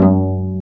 Index of /90_sSampleCDs/Roland - String Master Series/STR_Vcs Marc-Piz/STR_Vcs Pz.3 dry